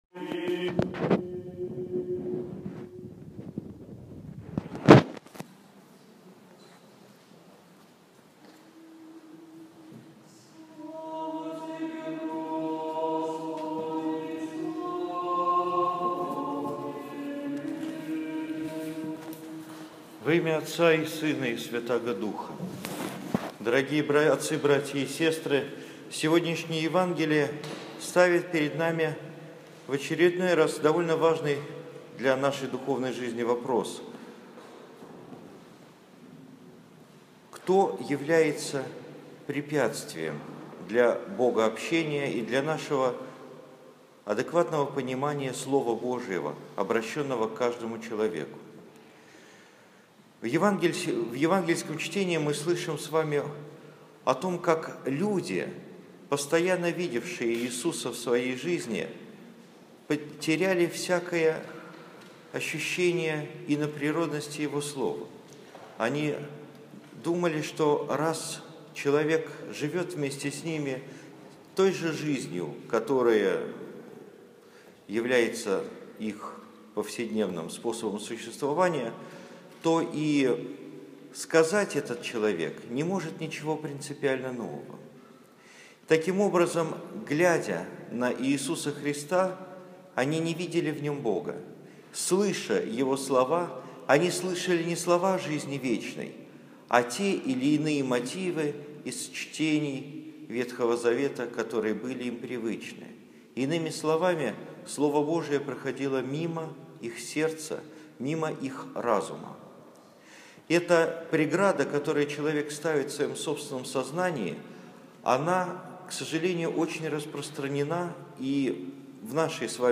13 июля 2015 года. 12 апостолов. Проповедь на литургии в Феодоровском соборе